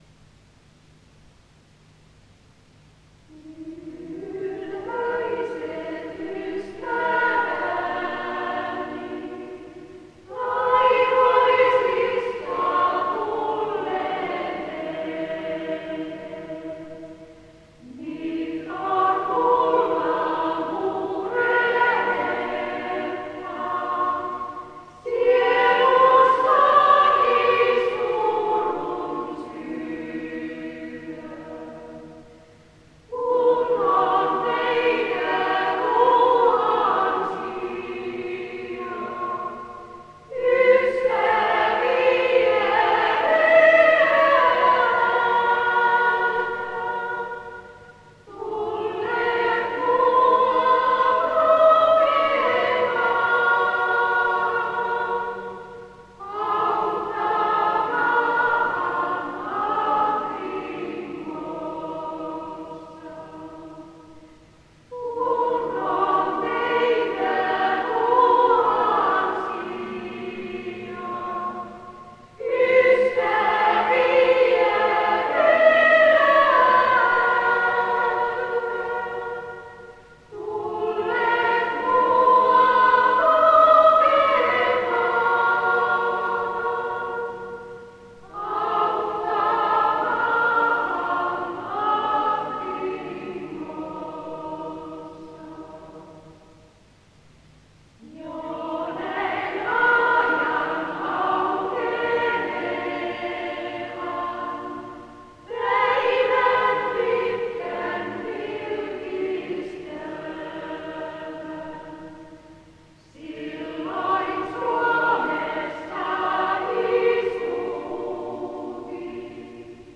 Musiikkiesityksiä; kuoro, yksinlauluja